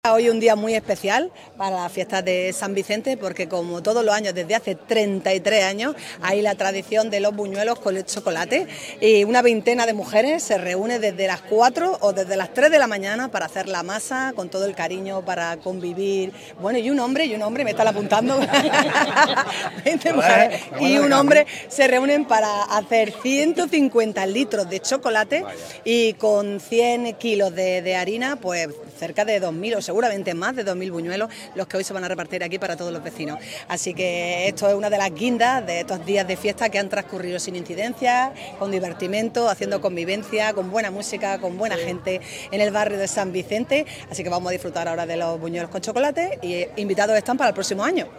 La alcaldesa participa en la tradicional buñuelada de las fiestas del barrio de San Vicente
CORTE-ALCALDESA-1.mp3